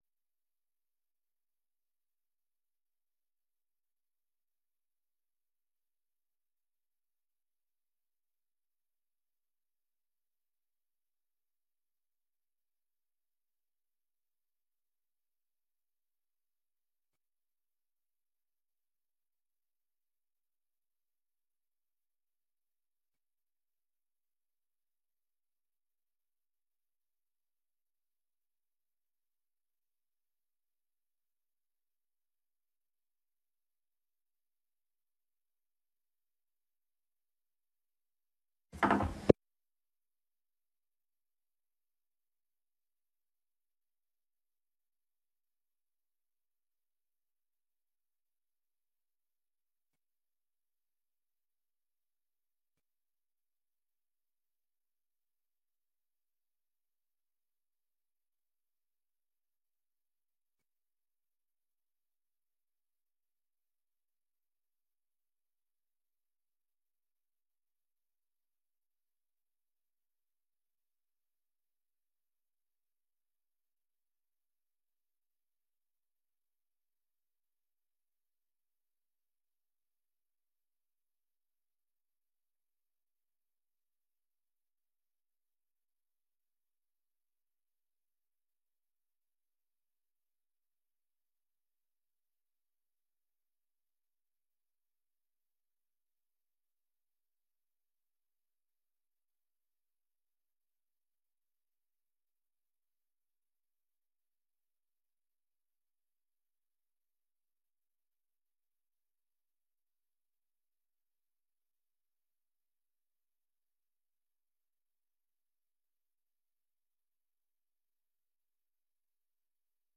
Vergadering van de commissie Bestuur, Financiën en Economie op maandag 18 september 2023, om 19.30 uur; eerst samen met de commissies Woonomgeving en Samenlevingszaken in de raadzaal, daarna vanf 20.15 uur fysiek in kamer 63 van het gemeentehuis.